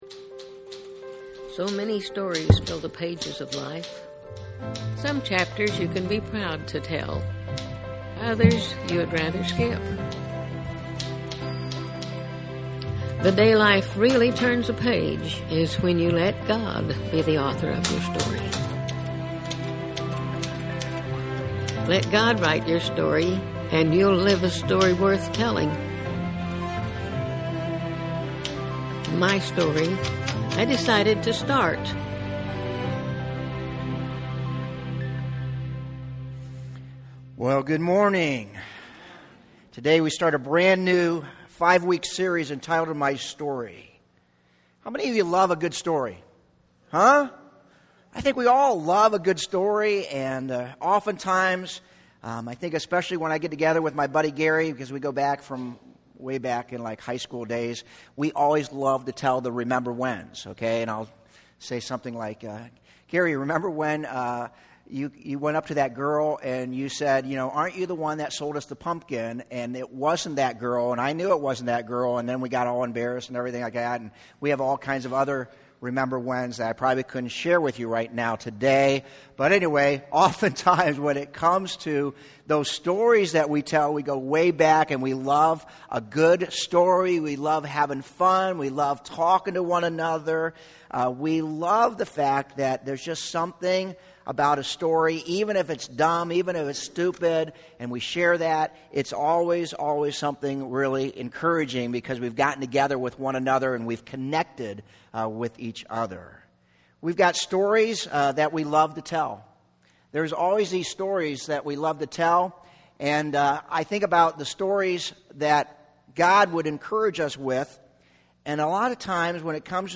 Medina Community Church Sermons